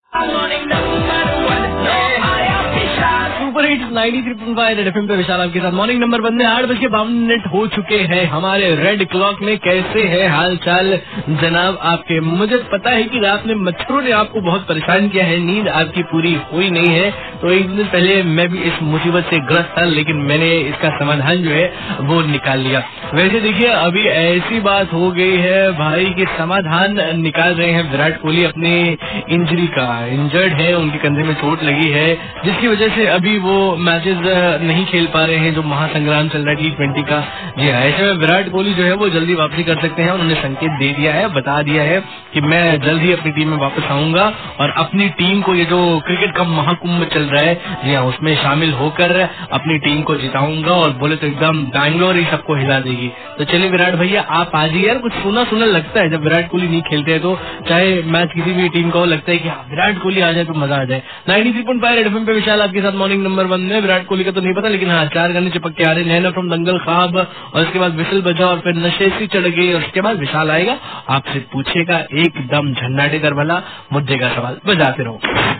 RJ ABOUT VIRAT KOHALI